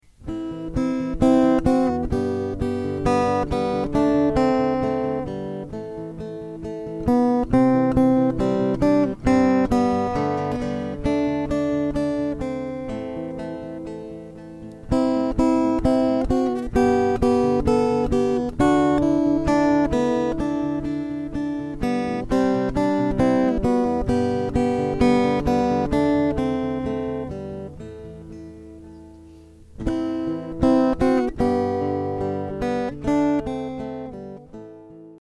It's all at Ashington Folk Club!